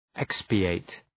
Προφορά
{‘ekspı,eıt}